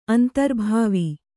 ♪ antarbhāvi